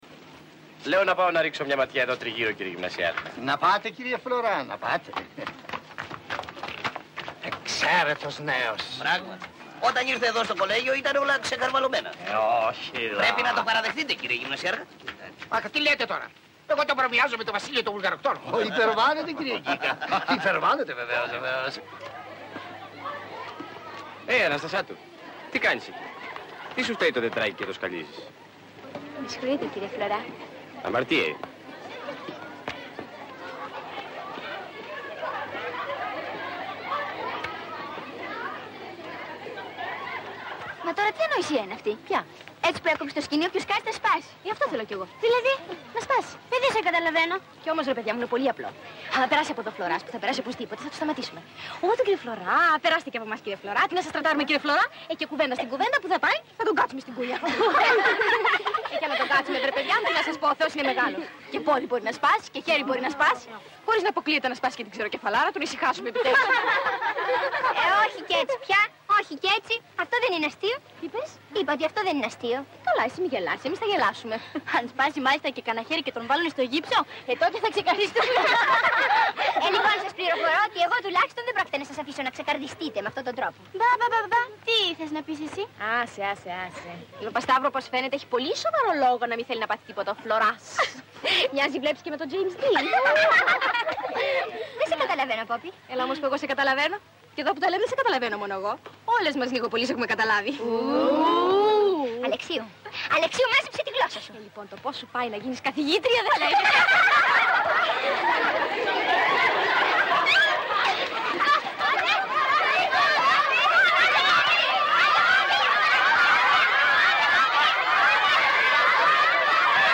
Πηγή: Ελληνική Ταινία
Ηθοποιοί: Αλίκη Βουγιουκλάκη, Δημήτρης Παπαμιχαήλ, Διονύσης Παπαγιαννόπουλος, Χρήστος Τσαγανέας, Ορέστης Μακρής